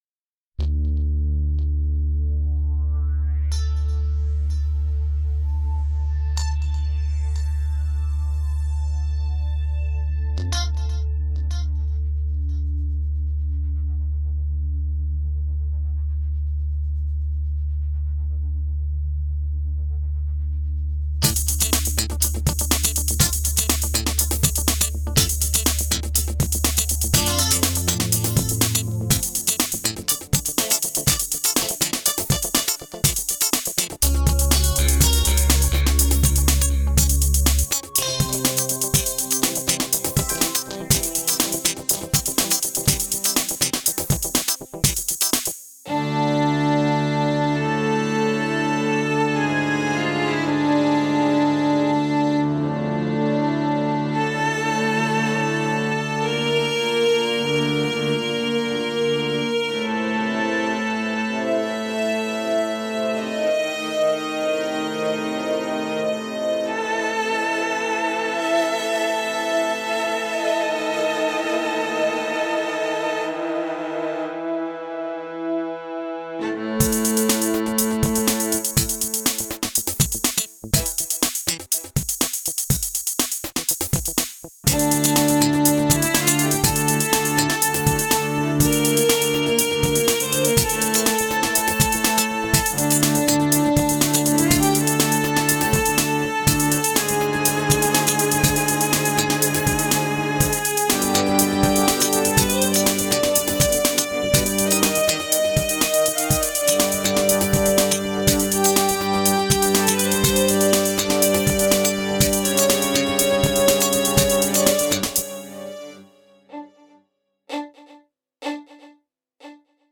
Духовная музыка Мистическая музыка Медитативная музыка